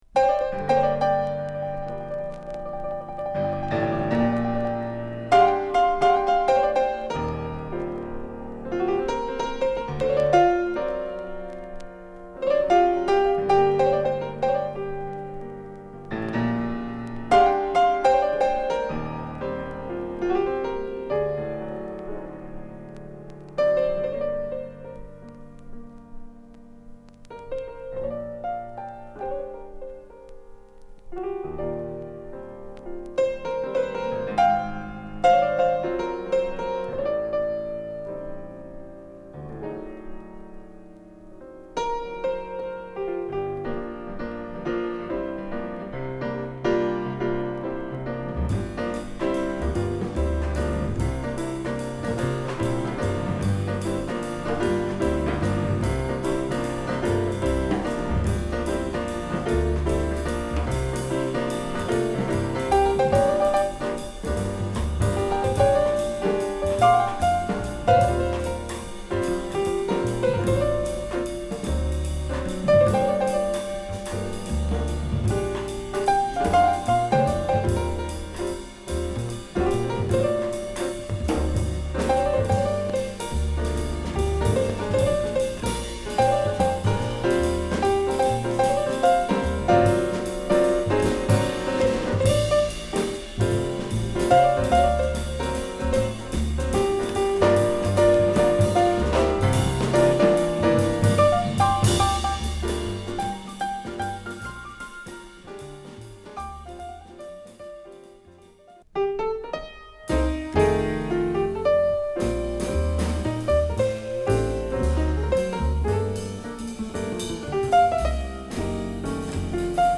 渡辺貞夫のグループでも活躍した岩手県出身のジャズ・ピアニスト